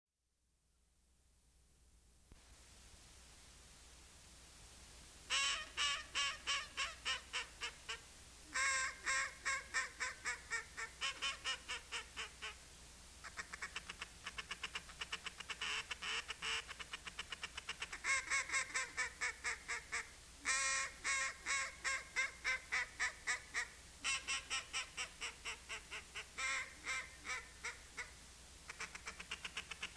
Duck ringtone
Duck call ringtone
01-Duck.mp3